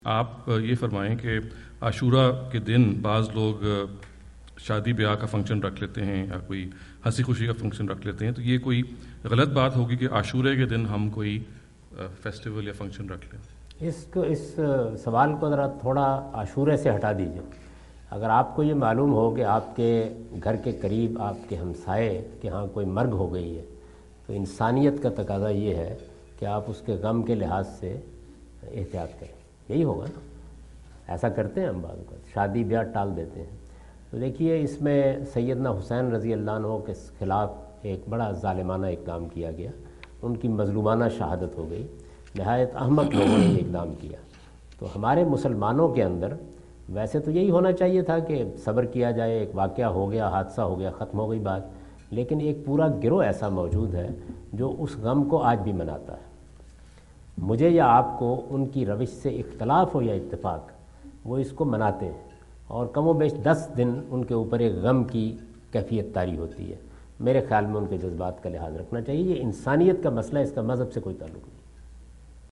Javed Ahmad Ghamidi answer the question about "Wedding Ceremony on 10th of Muharram" During his US visit in Dallas on October 08,2017.
جاوید احمد غامدی اپنے دورہ امریکہ2017 کے دوران ڈیلس میں "10 محرم کو شادی کی تقریبات" سے متعلق ایک سوال کا جواب دے رہے ہیں۔